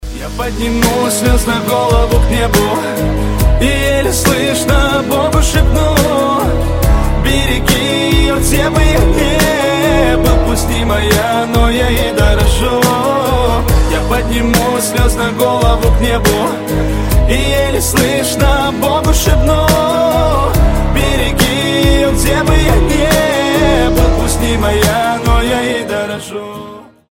мужской вокал
красивые
душевные
романтичные
медляк
трогательные